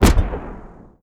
EXPLOSION_Arcade_17_mono.wav